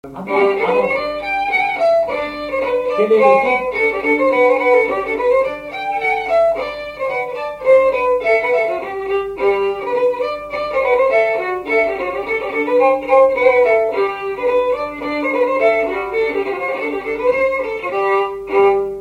Mémoires et Patrimoines vivants - RaddO est une base de données d'archives iconographiques et sonores.
Avant-deux
Résumé instrumental
gestuel : danse